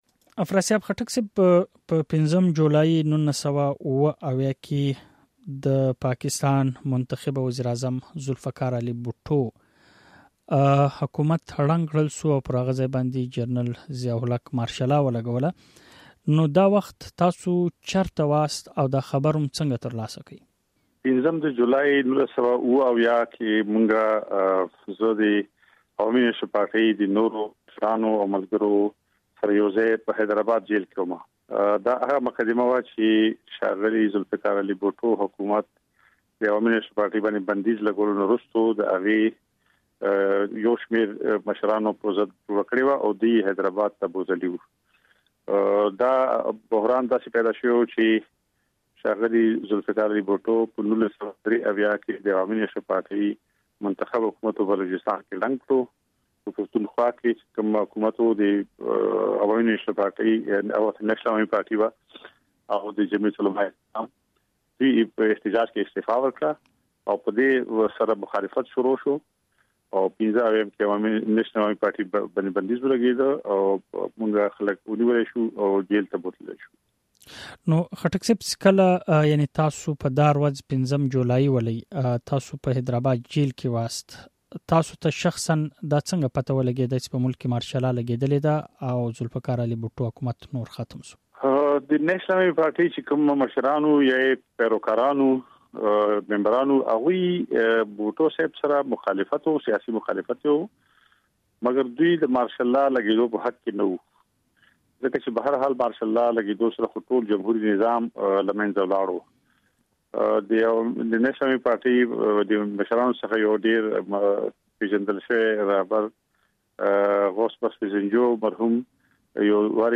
د سنېټر افراسیاب خټک مرکه دلته واورئ